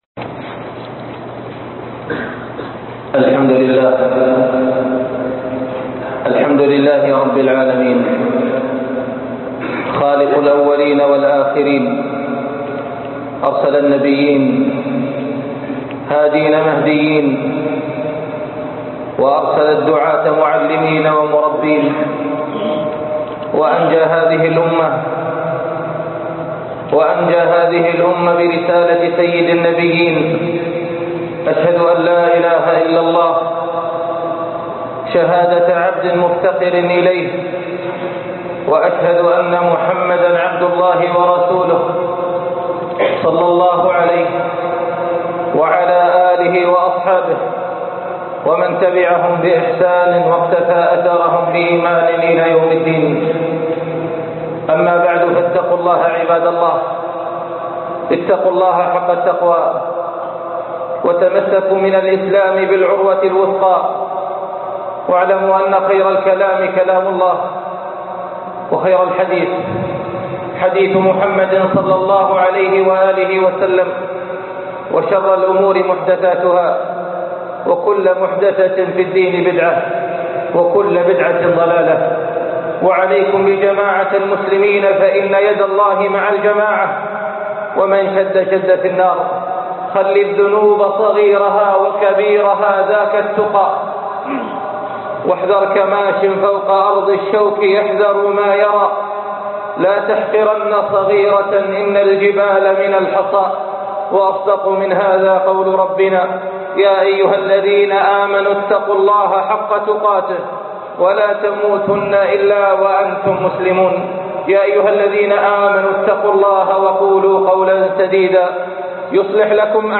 من الطارق - خطب الجمعة